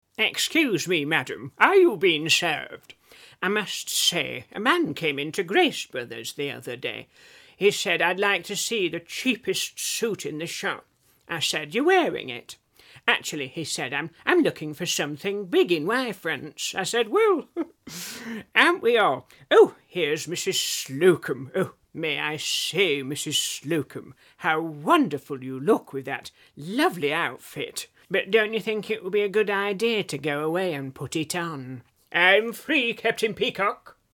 Steve Nallon as John Inman